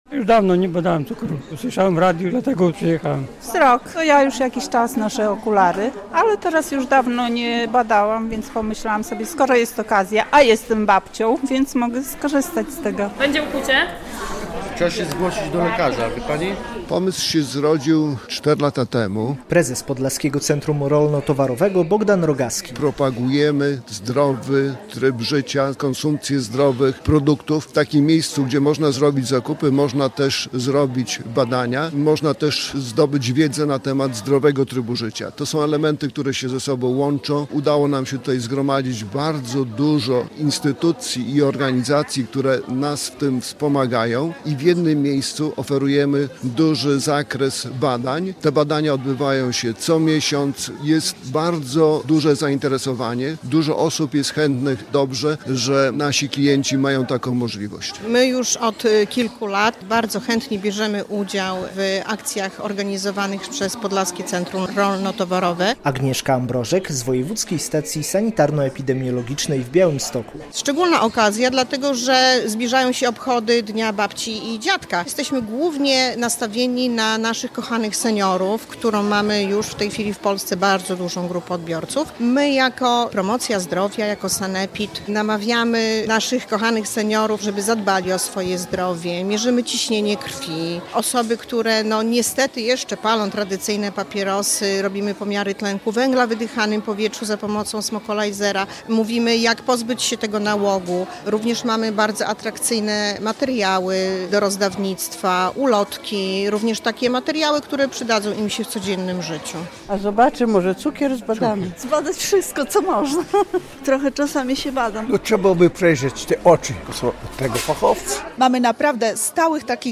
Badania na giełdzie przy Andersa - relacja